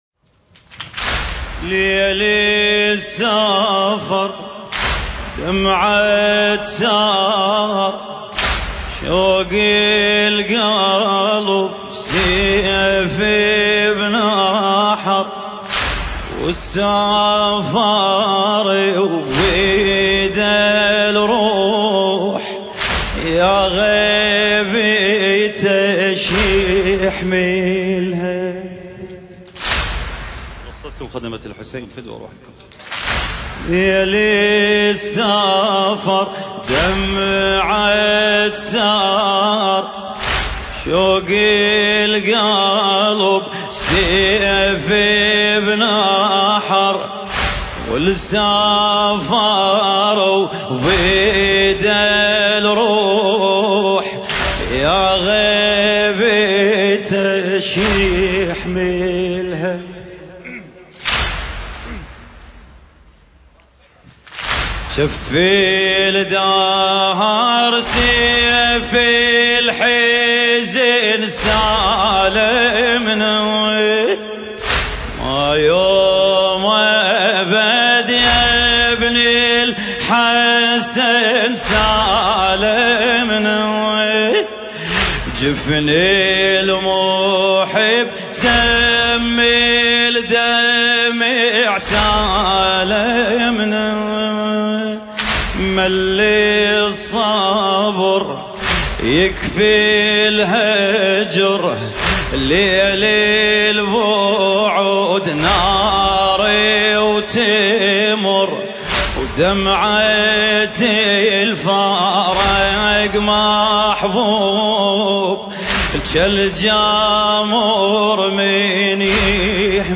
المكان: عزاء شباب اهالي كربلاء المقدسة